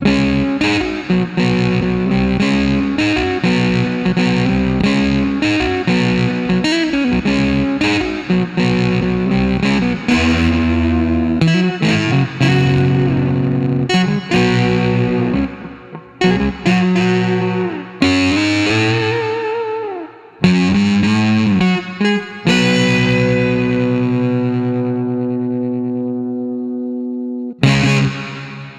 100Bpm老式摇滚吉他
描述：摇滚吉他。C小调的6个步骤
Tag: 100 bpm Rock Loops Guitar Electric Loops 4.85 MB wav Key : C